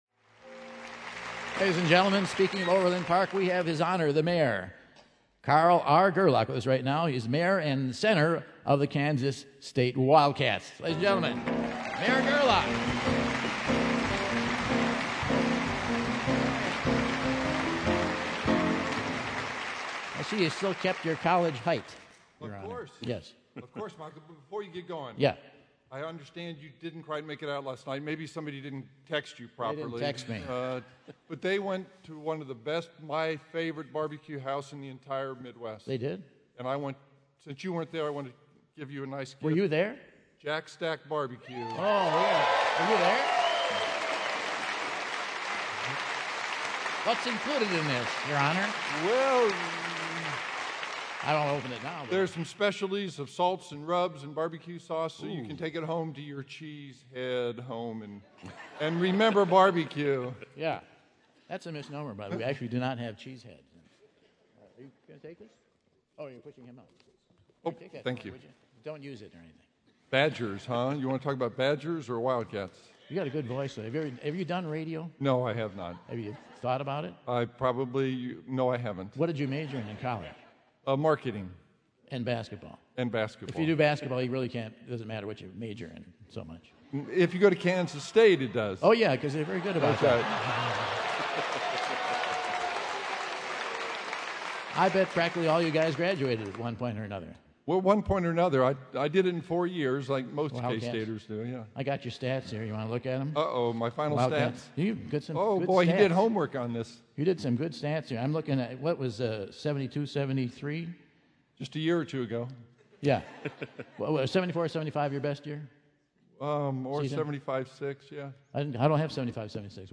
Overland Park, KS Mayor Carl Gerlach brings Michael some BBQ and shares the success stories of Overland Park, KS!